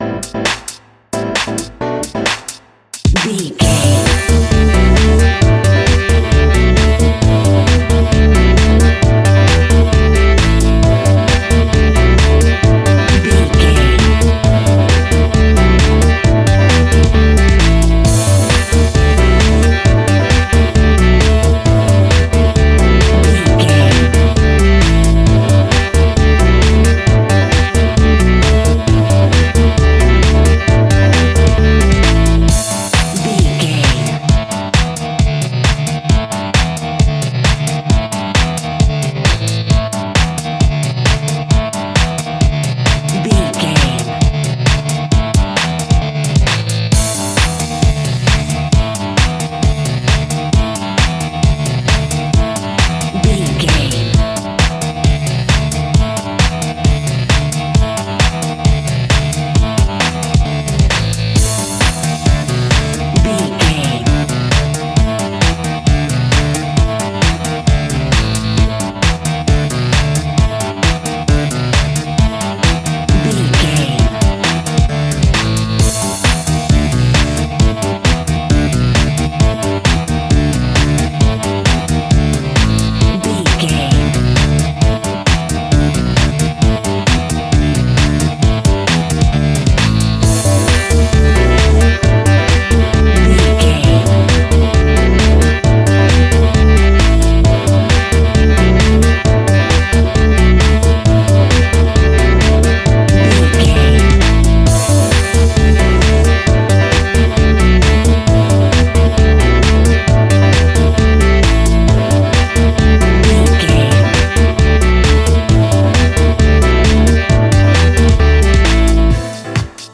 Aeolian/Minor
groovy
futuristic
hypnotic
uplifting
drum machine
synthesiser
electro house
funky house
synth bass
synth leads
percussion
guitar